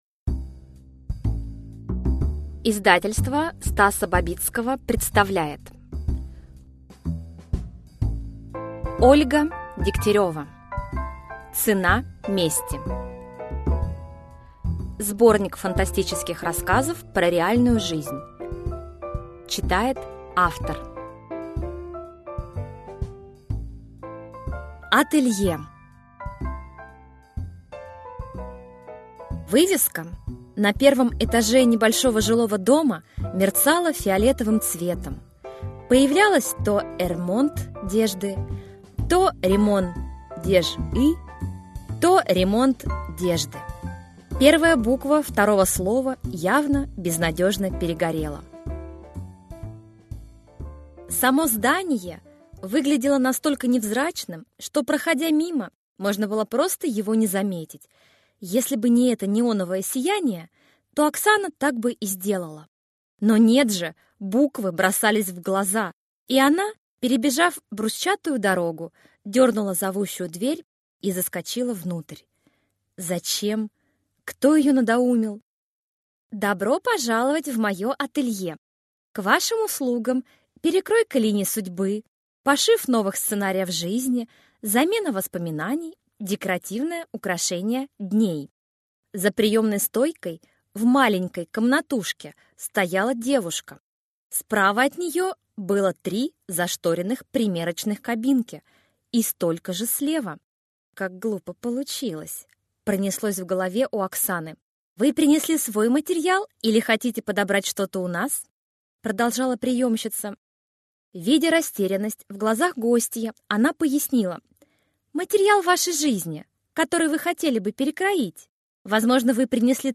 Аудиокнига Цена мести | Библиотека аудиокниг